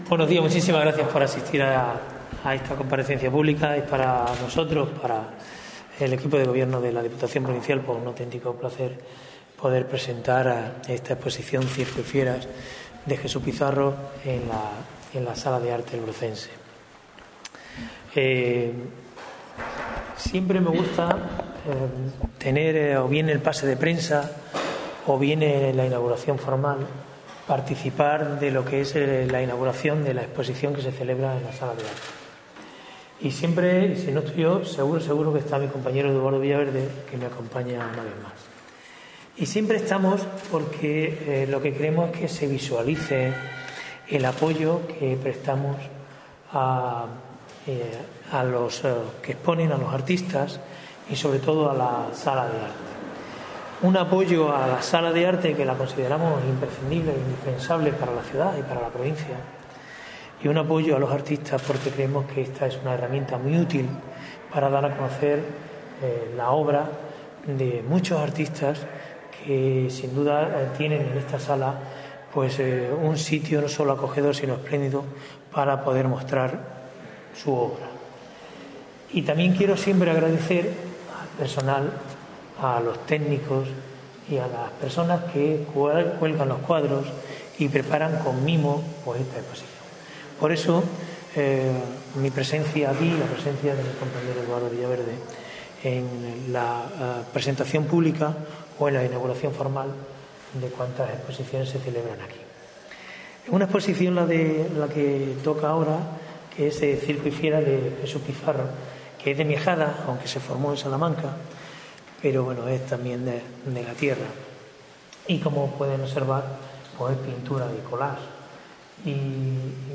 CORTES DE VOZ
EXPOSICIxN_xCIRCO_DE_FIERASx.mp3